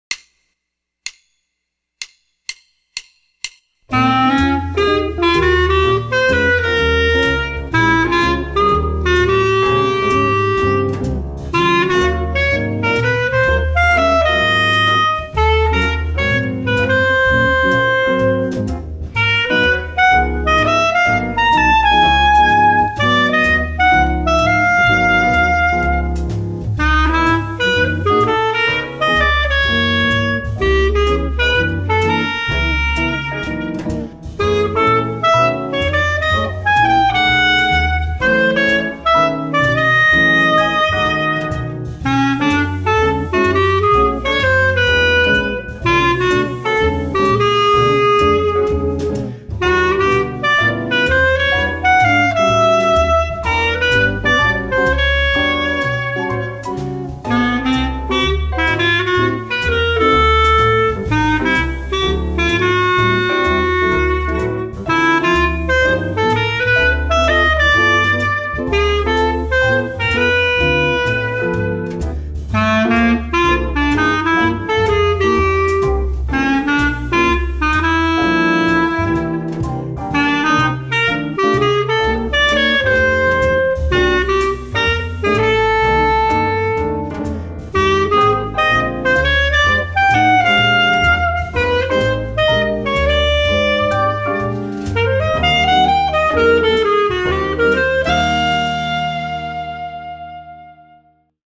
Whereas the original was rather languid and lyrical, this variation is aggressive, swinging, and syncopated.  The tempo is faster, and the pattern uses four bars, instead of two.
In the exuberance of playing, I accidentally changed note #8 from a B natural to a Db.
By the way, I recorded all of these tracks without charts.